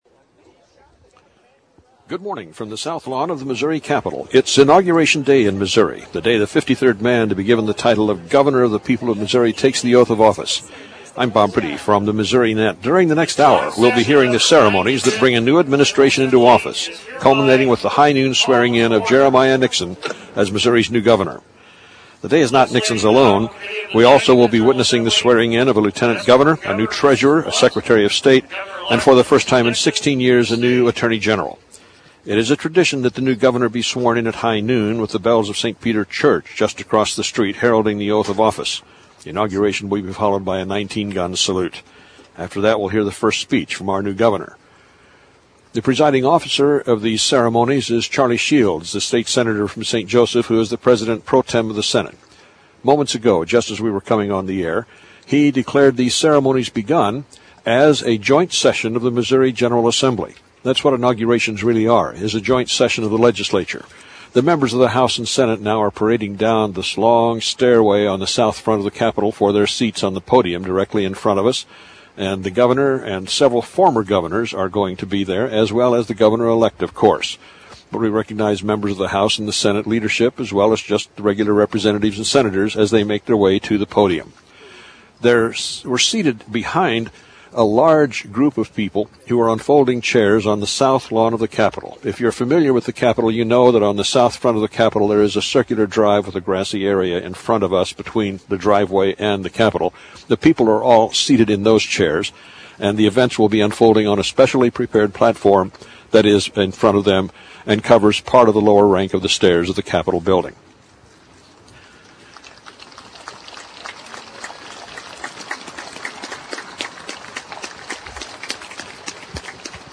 Jay Nixon is officially Missouri’s 55th governor. Nixon took the oath of office slightly before noon today during ceremonies on the south lawn of the State Capitol. State Supreme Court Chief Justice Laura Denvir Stith administered the oath.
Nixon then addressed the crowd, striking the theme "A New Day for Missouri". Nixon says the difficult economic situation has united Missourians as never before.
Nixon_Inauguration2009.mp3